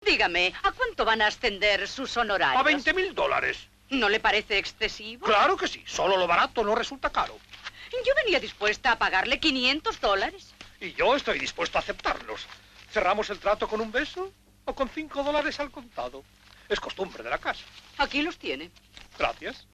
SONIDO (VERSIÓN DOBLADA)
Presentada también en monoaural 1.0, esta pista tiene menos ruido de fondo que otras películas de los Marx, por lo que suena en principio ligeramente mejor.
oír aquí un fragmento de un diálogo del film, para comparar las voces con las de otras películas de los Marx.